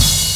73 LIVE O_AM CRASH.wav